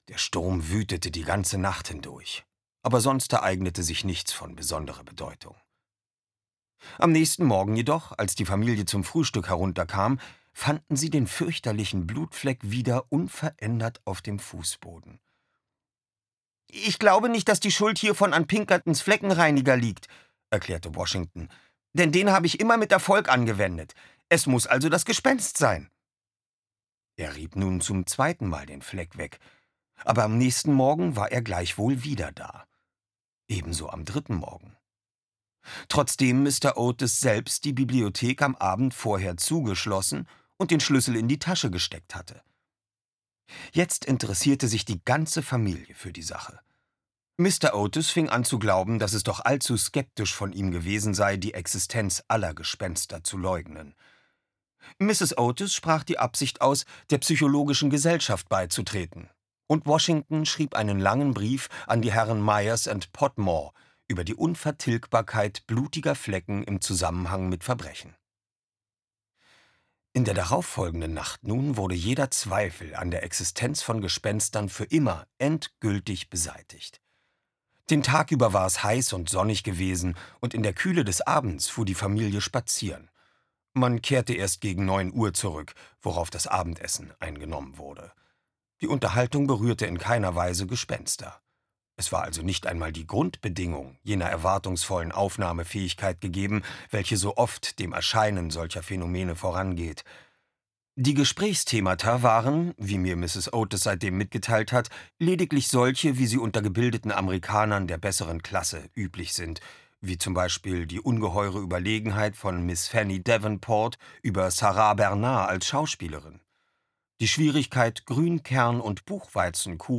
Oliver Rohrbeck (Sprecher)
In dieser Lesung nimmt sich "Drei ???"-Sprecher Oliver Rohrbeck der Familie Otis und dem Schicksal Sir Simons an.